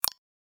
click.aac